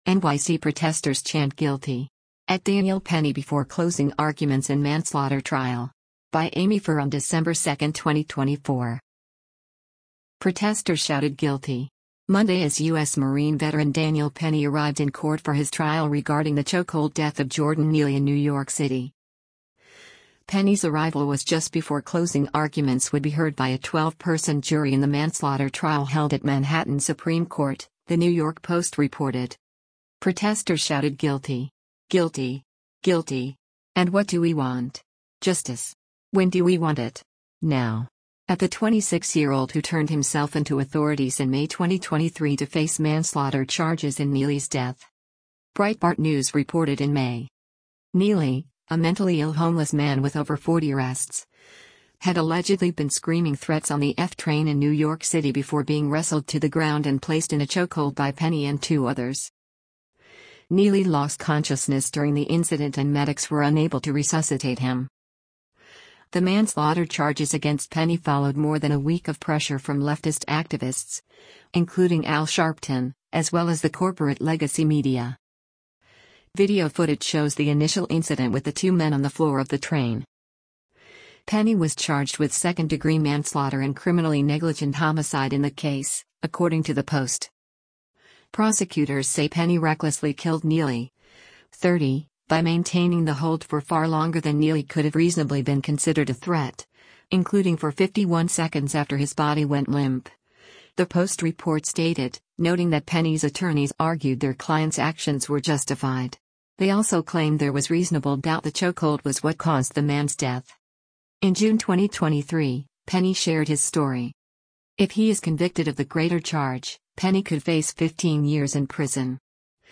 NYC Protesters Chant ‘Guilty!’ at Daniel Penny Before Closing Arguments in Manslaughter Trial
Protesters shouted “Guilty!” Monday as U.S. Marine veteran Daniel Penny arrived in court for his trial regarding the chokehold death of Jordan Neely in New York City.
Protesters shouted “Guilty! Guilty! Guilty!” and “What do we want? Justice! When do we want it? Now!” at the 26-year-old who turned himself in to authorities in May 2023 to face manslaughter charges in Neely’s death.